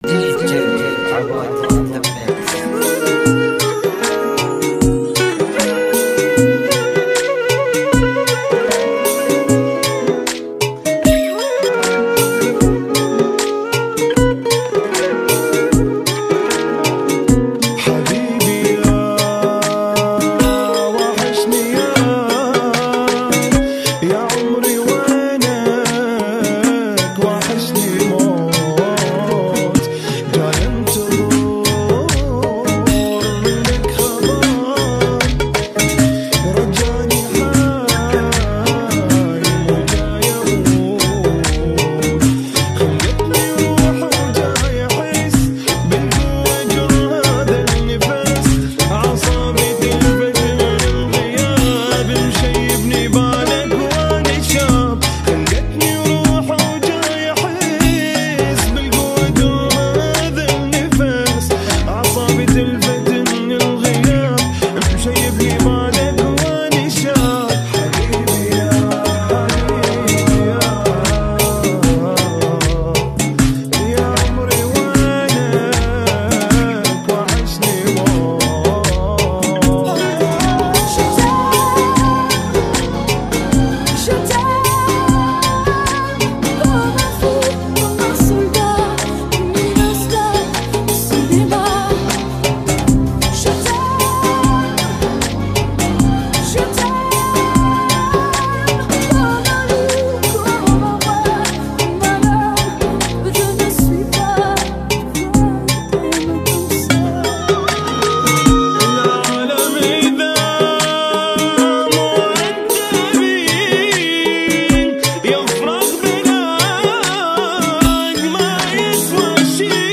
ريمگس